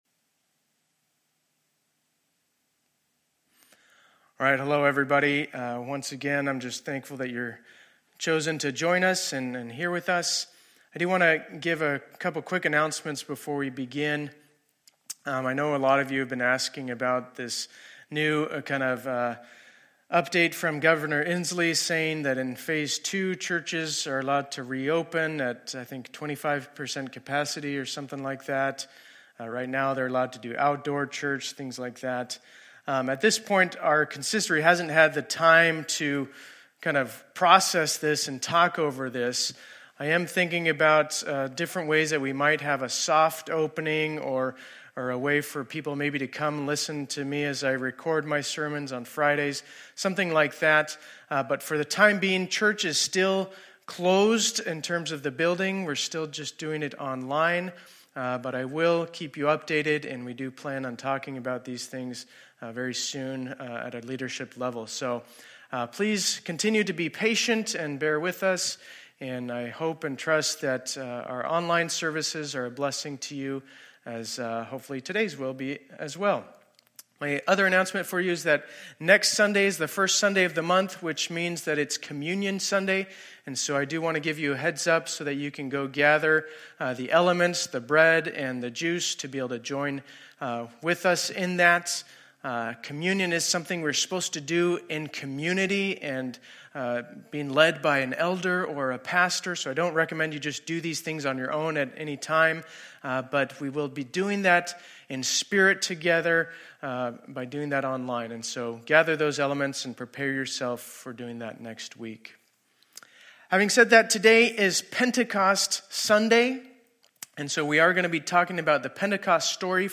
2020-05-31 Sunday Service